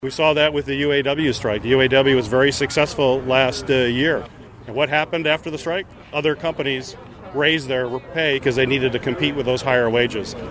The motorcycle tour began at IBEW Local 131 in Kalamazoo.